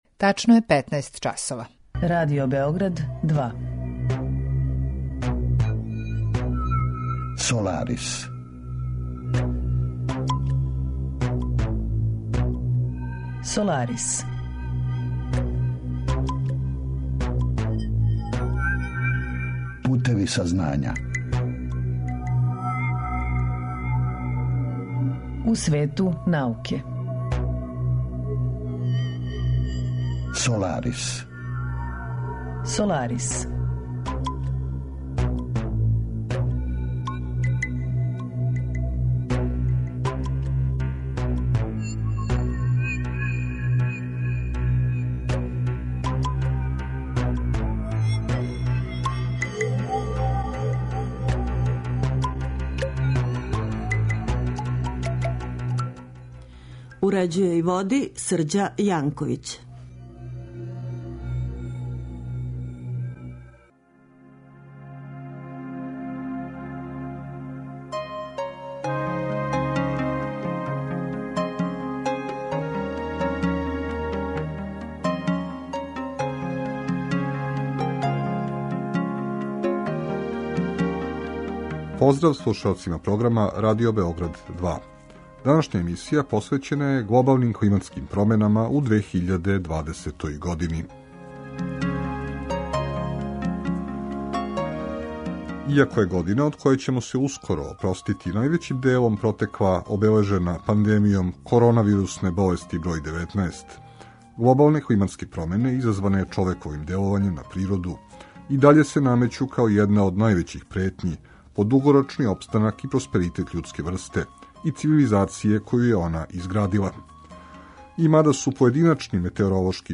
Саговорници у емисији обухватају научнике који се баве истраживањима из различитих области, од носилаца врхунских резултата и признања до оних који се налазе на почетку свог научног трагања, али и припаднике разноврсних професија који су у прилици да понуде релевантна мишљења о одговарајућим аспектима научних подухвата и науке у целини.